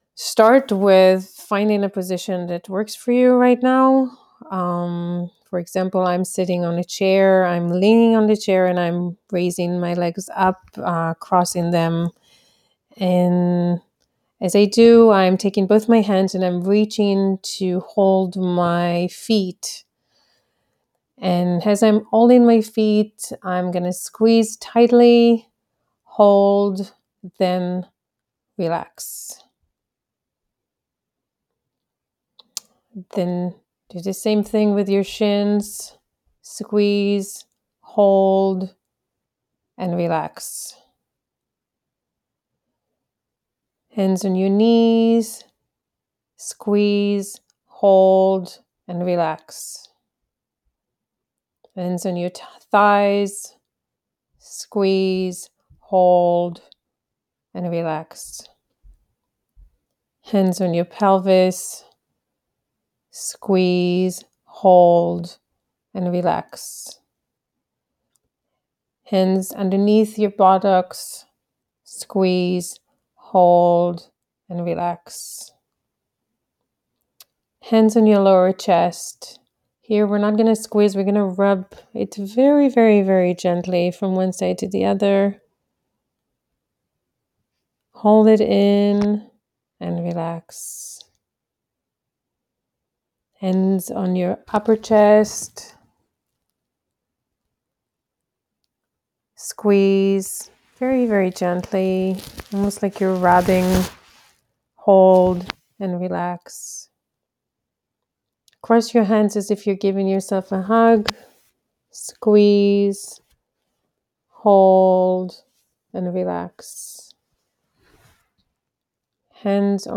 relaxing meditation